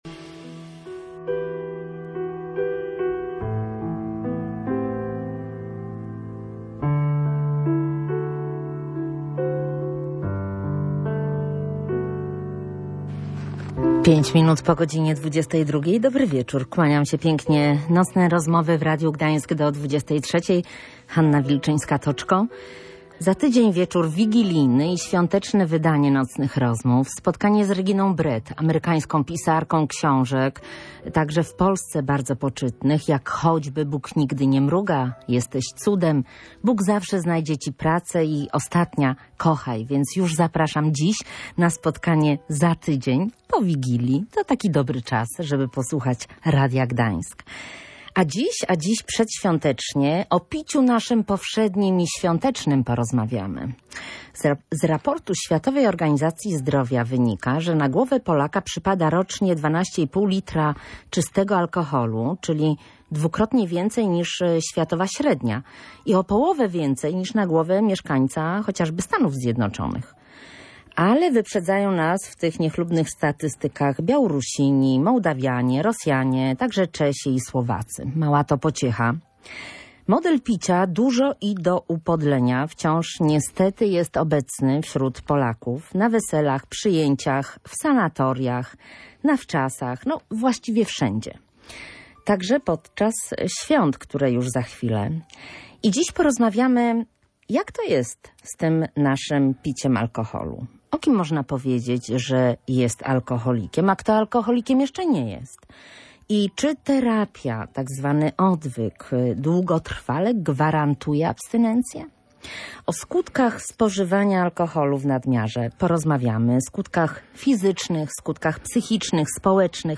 Rozmowa dotyczyła tego, kto już jest, a kto jeszcze nie – alkoholikiem. Jakie skutki psychiczne, fizyczne, społeczne powoduje nadużywanie alkoholu i czy terapia gwarantuje abstynencję.